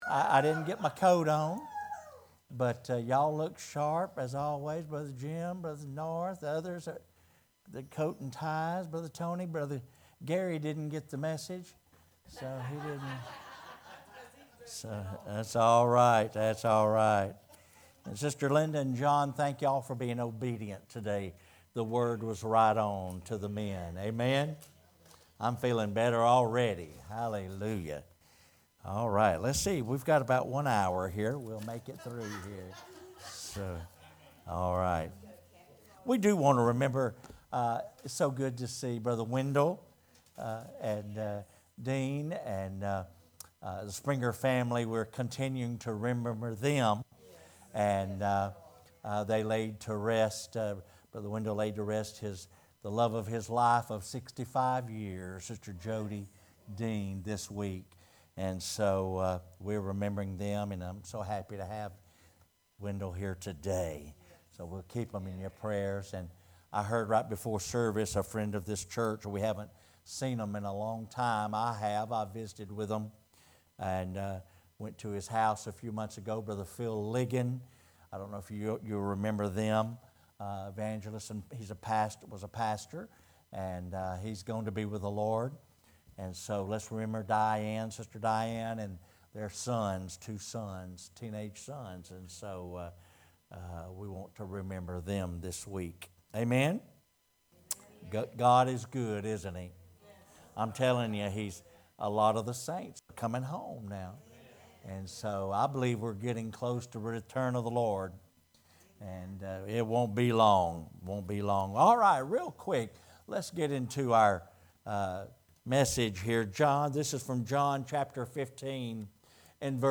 Sermons | New Life Ministries